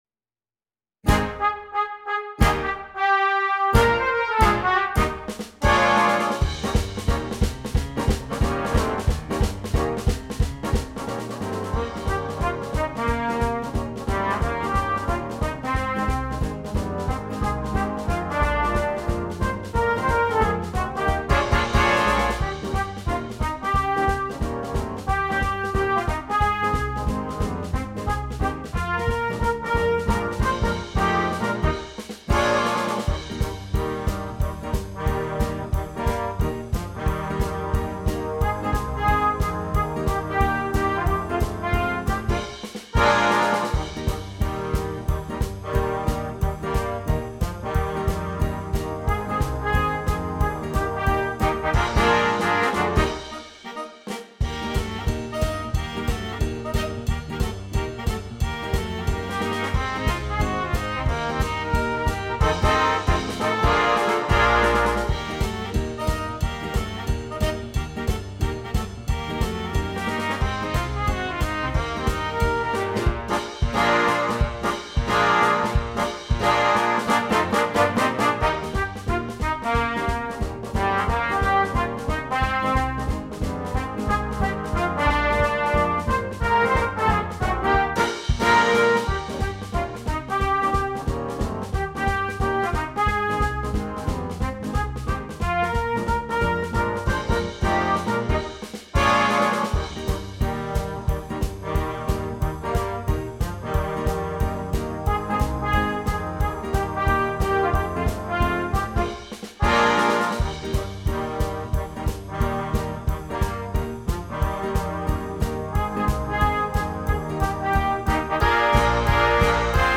Jazz Band
arranged in the style of rockabilly artists
There are few short solo moments for Trumpet.